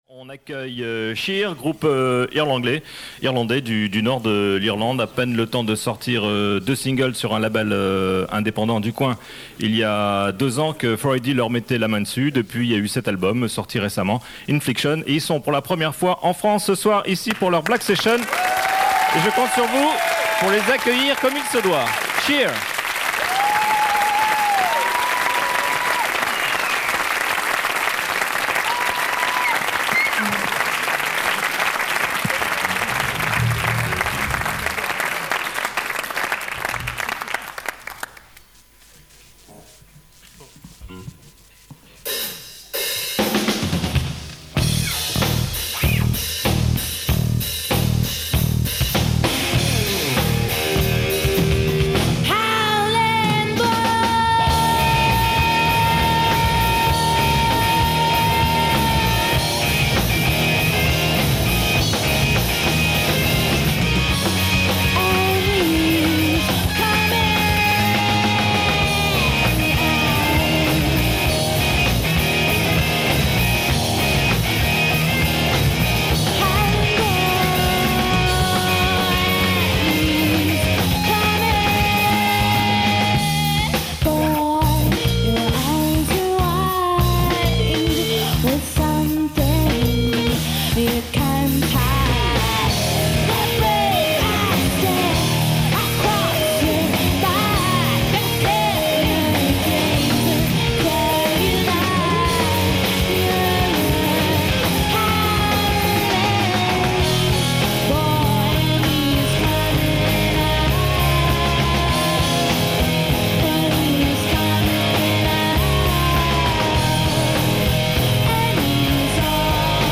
enregistrée le 28/05/1996  au Studio 105